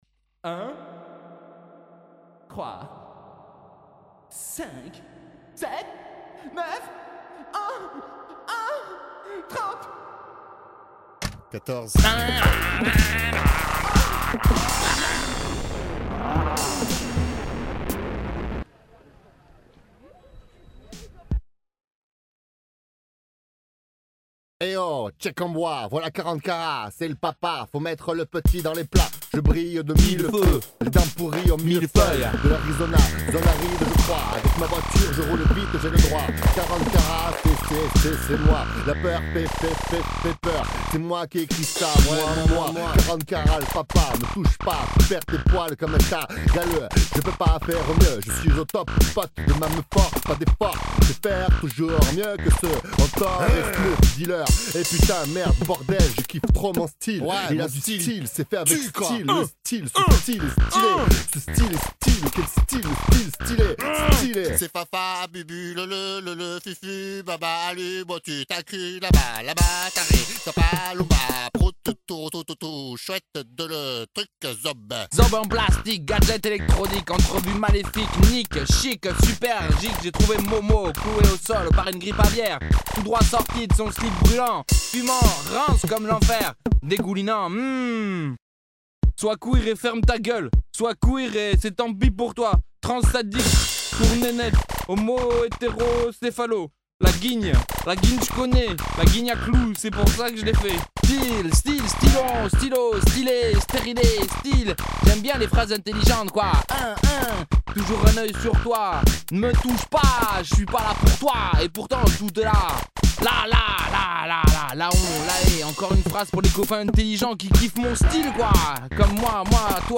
Yo Yo Yo, ghetto, cacao, du rap chelou et BUENO.